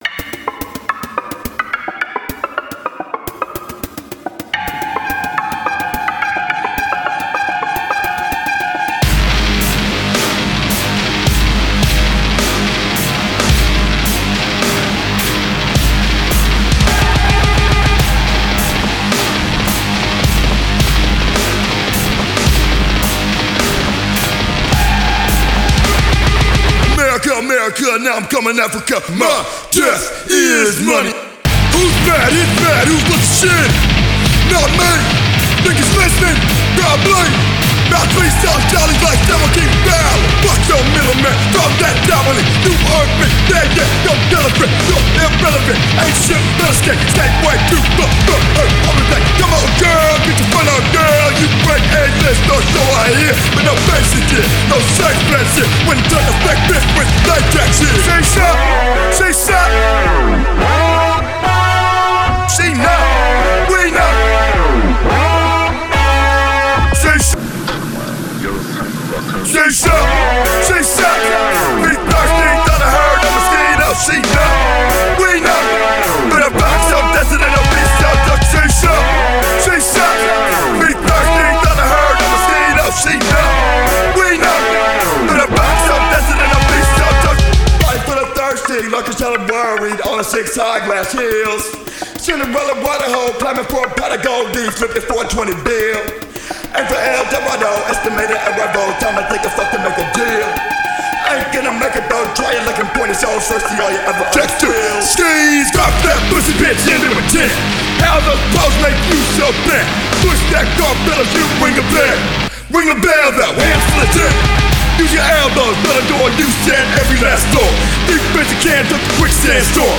Жанр: Hip-Hop.